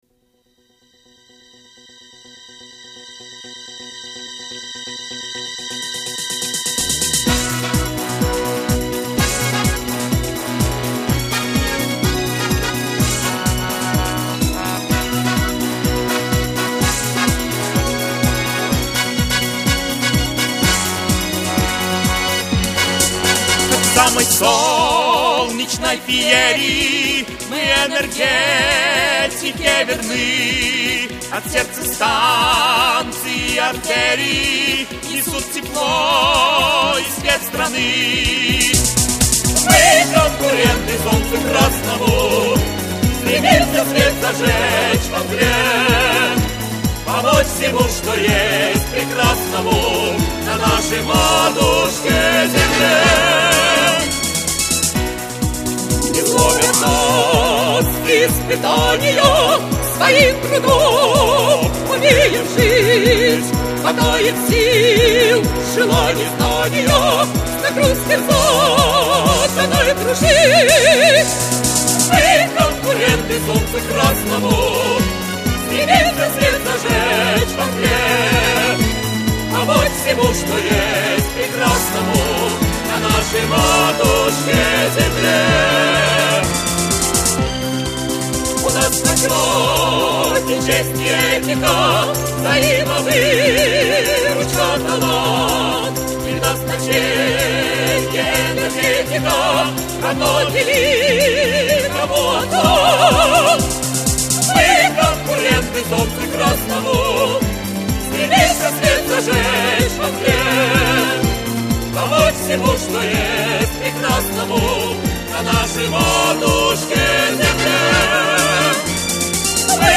исполняют солисты Омского музыкального теара